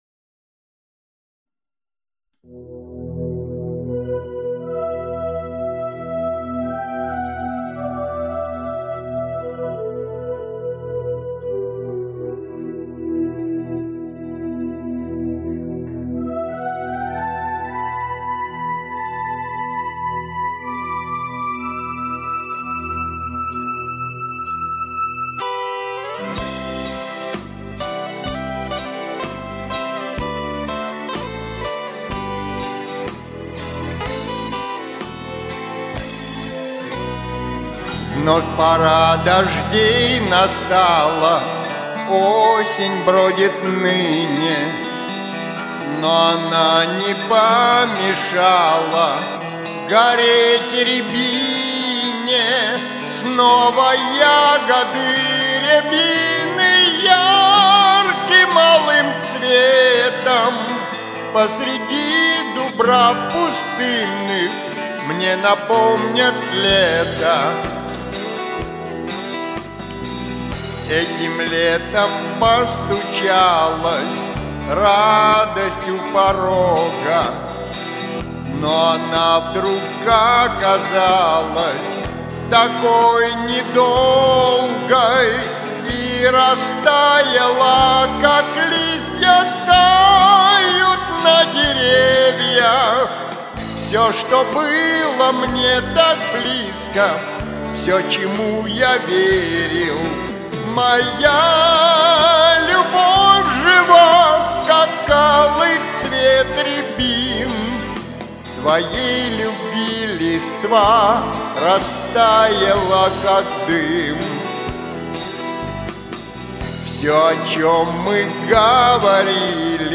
более резкое горловое